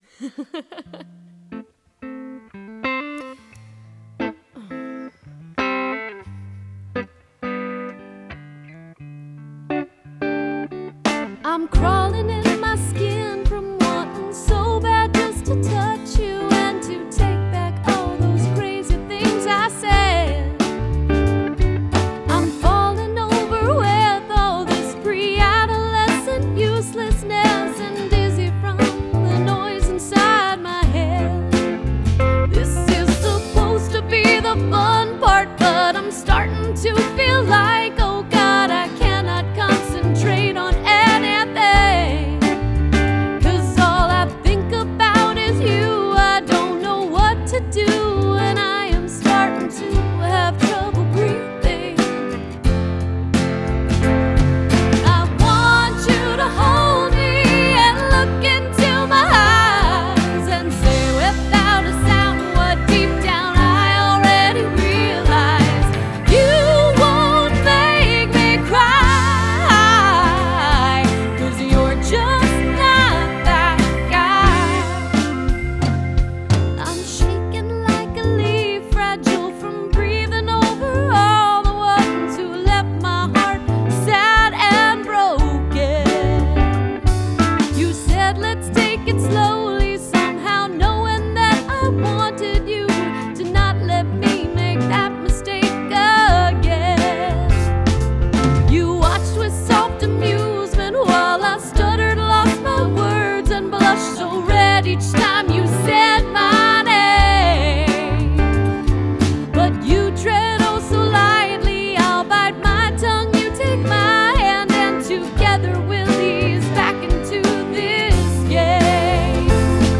Жанр: Pop, Indie Pop, Country, Singer-Songwriter